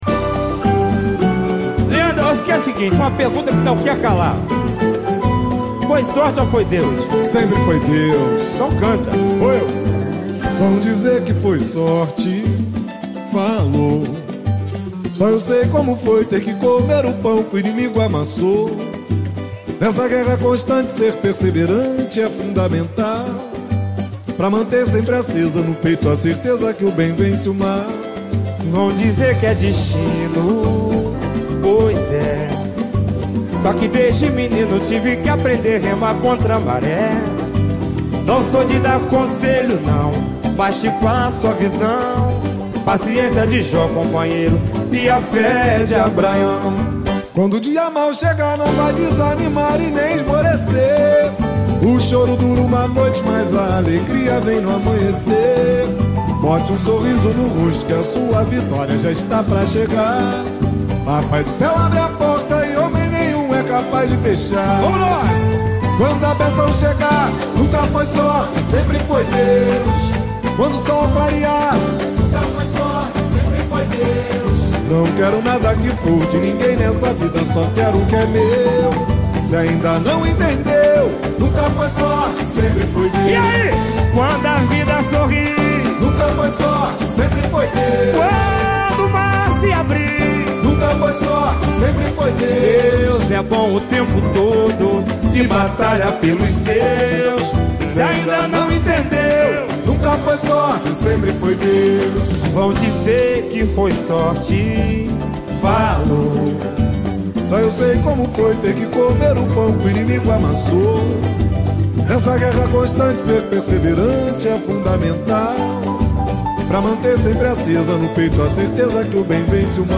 CAVACO O CORAÇÃO DO SAMBA
VOCALISTA